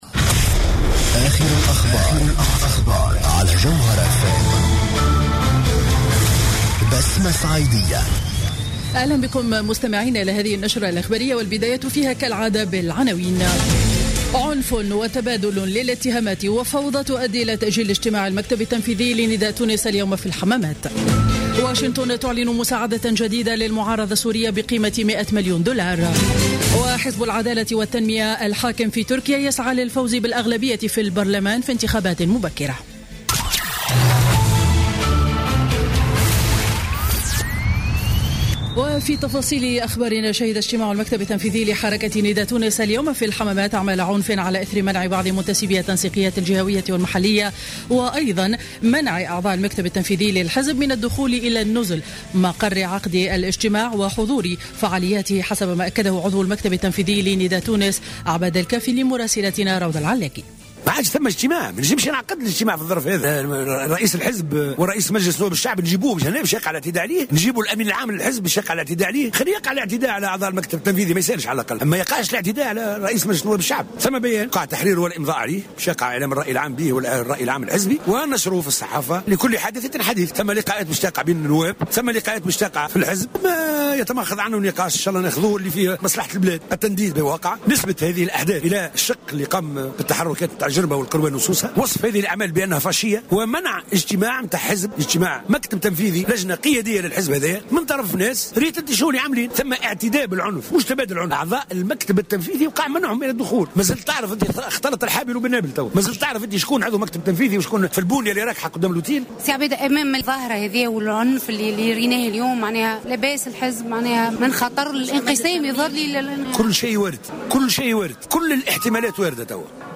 نشرة منتصف النهار ليوم الأحد غرة نوفمبر 2015